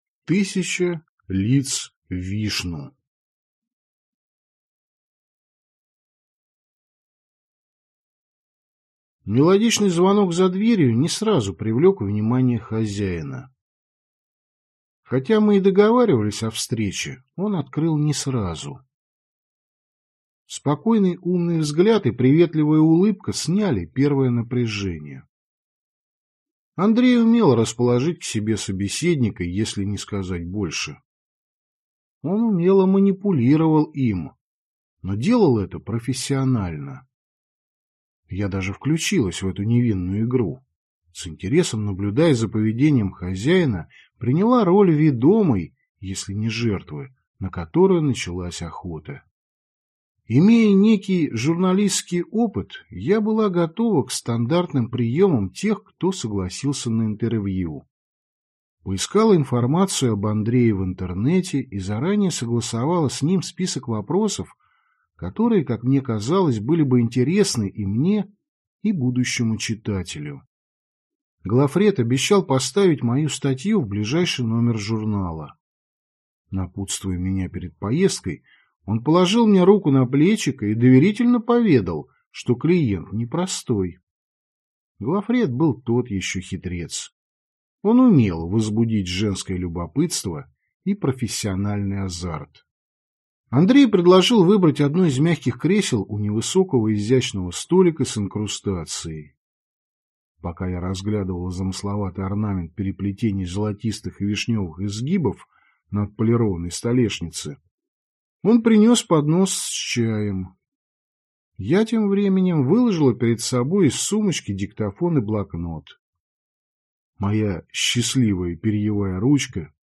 Аудиокнига Листы одного древа (сборник) | Библиотека аудиокниг